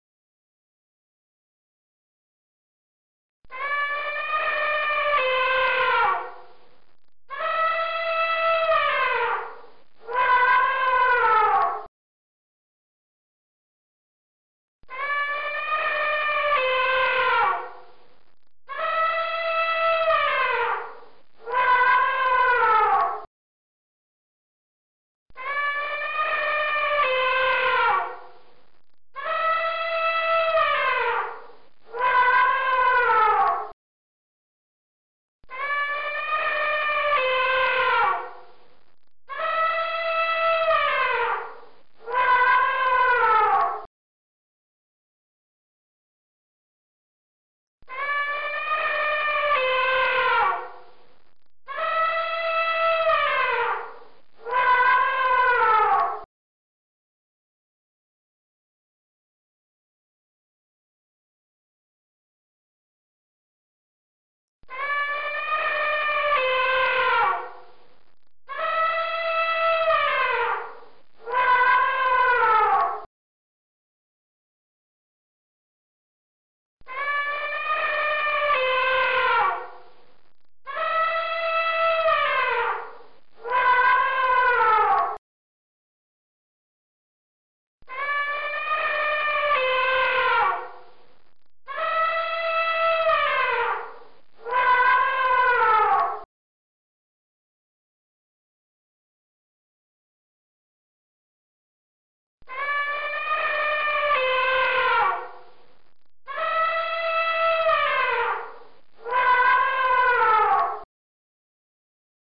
Elephant Trumpeting Check out sound effects free download